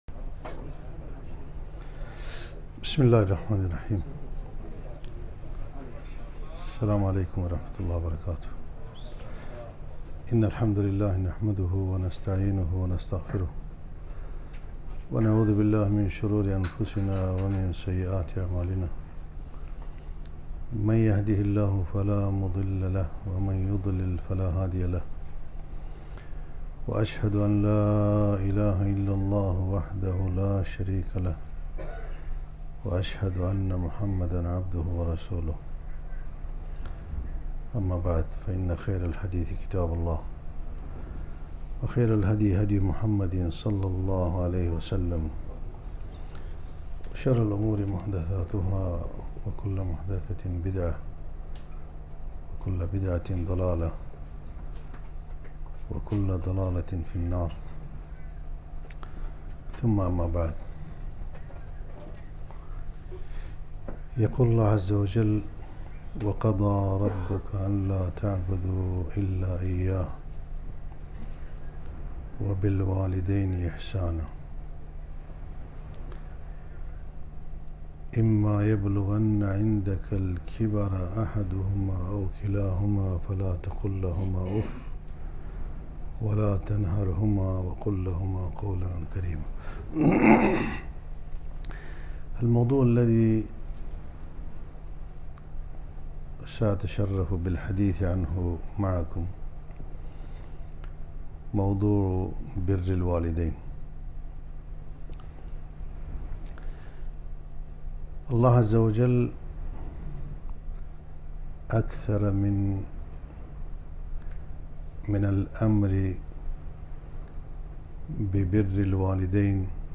محاضرة - بـرُّ الـوالـديـن في سـيـاقـات الـقـرآن الـكـريـم